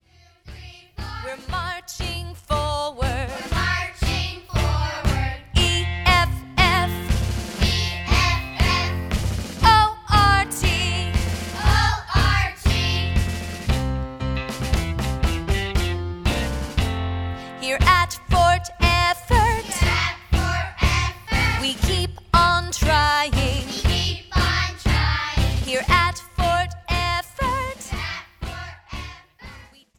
• MP3 of both vocals and instrumental